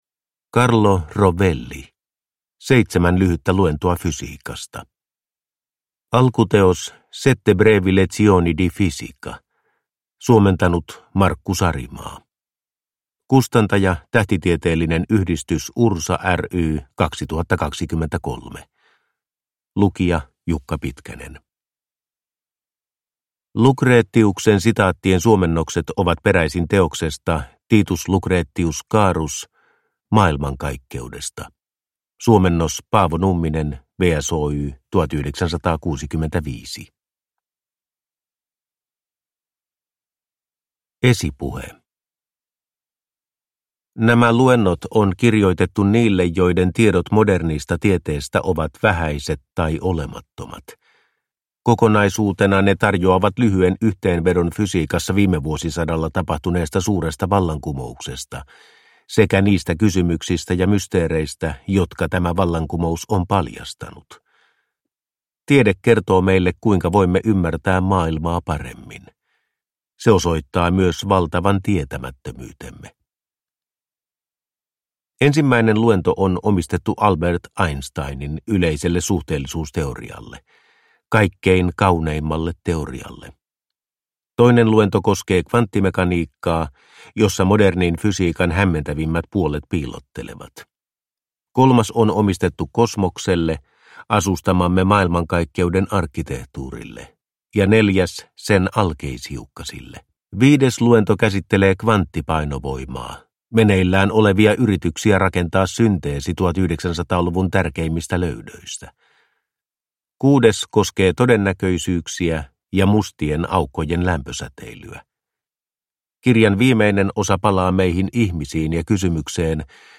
Seitsemän lyhyttä luentoa fysiikasta – Ljudbok – Laddas ner